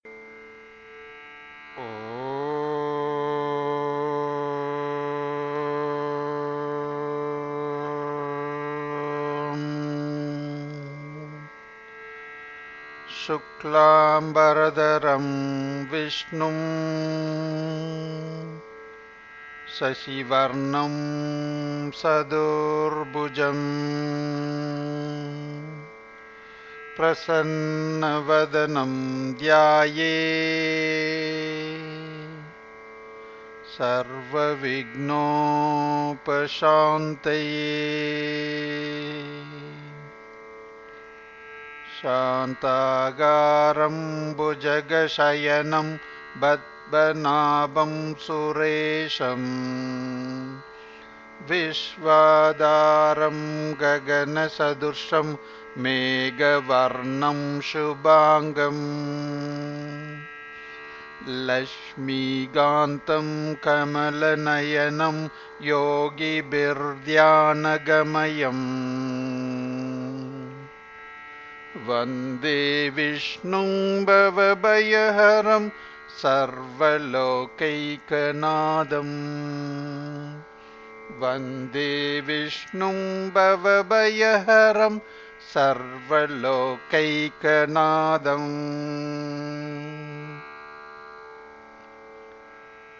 Bajanai-Padalgal-A